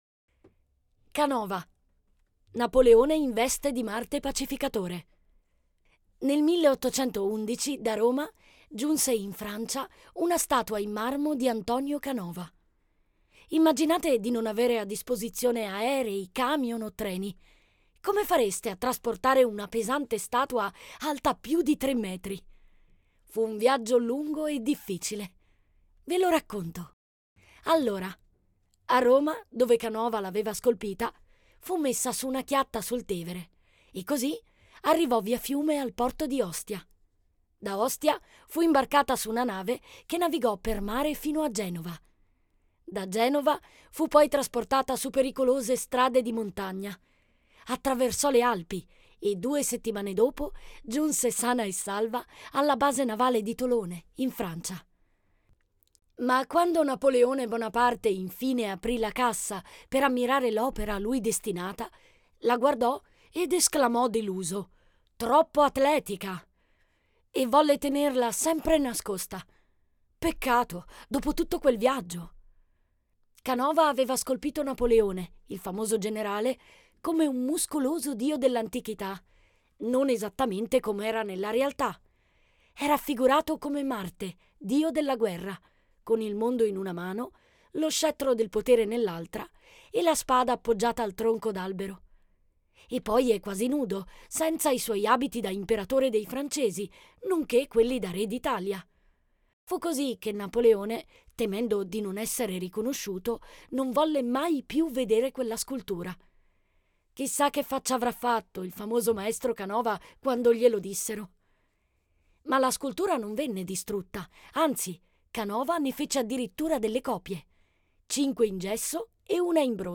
AI Audio
Audioguida "Brera in famiglia"